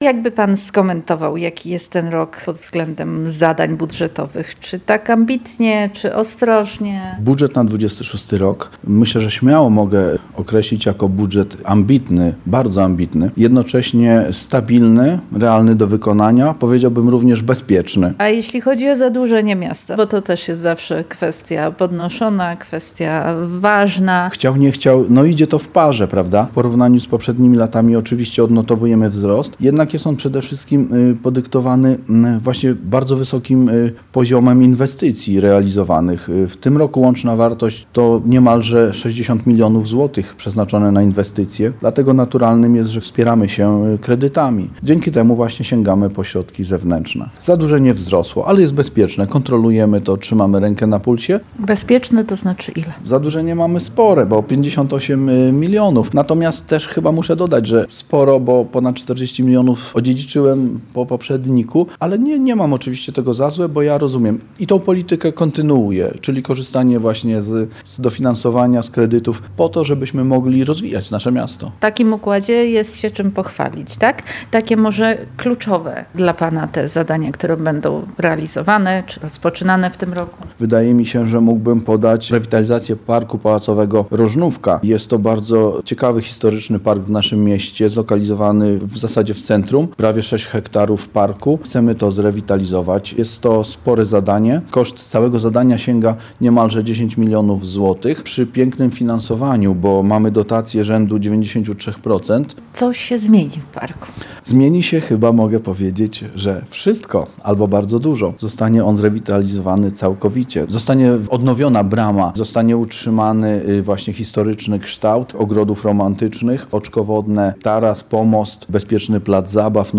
Inwestycje i rozwój w trudnych czasach. Rozmowa z burmistrzem Biłgoraja